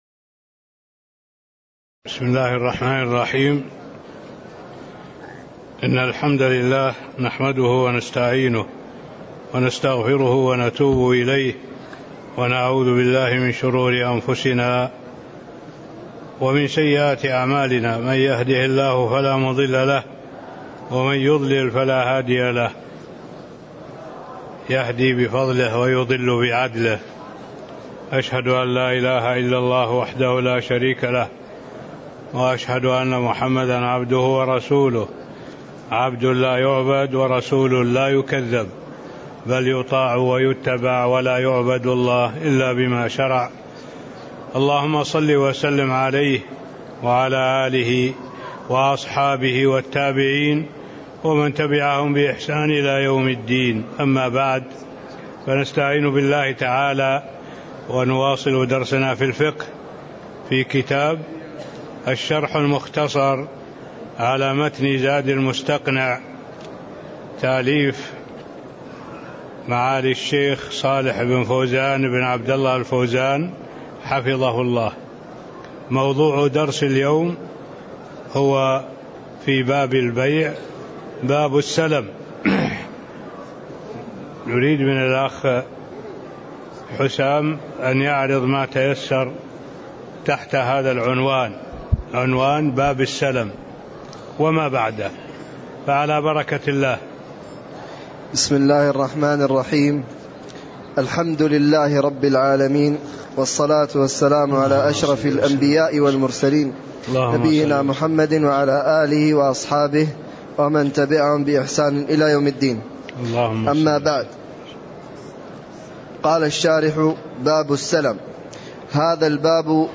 تاريخ النشر ١٧ ربيع الأول ١٤٣٥ هـ المكان: المسجد النبوي الشيخ: معالي الشيخ الدكتور صالح بن عبد الله العبود معالي الشيخ الدكتور صالح بن عبد الله العبود باب السلم (06)